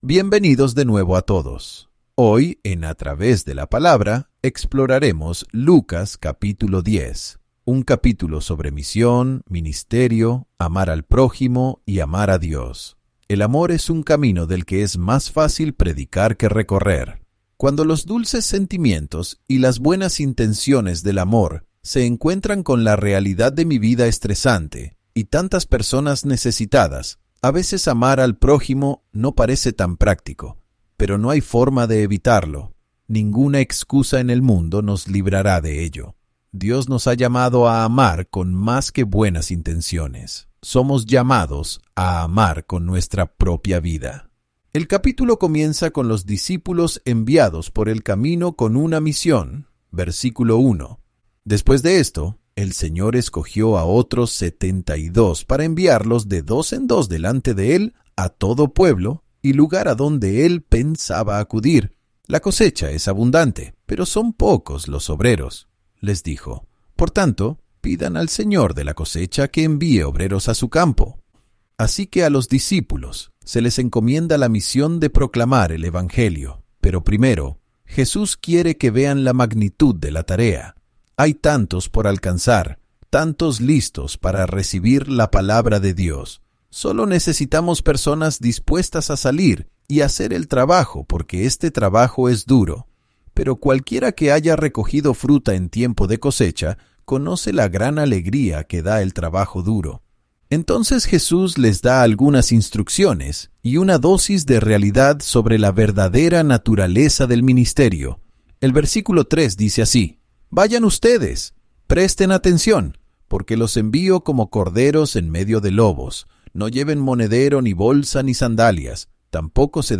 Descargo de Responsabilidad En el capítulo 10, versículo uno, nos vimos forzados a cambiar la palabra “ir” por “acudir” debido a que el sintetizador de voz generado por IA no lograba pronunciar la palabra “ir” correctamente.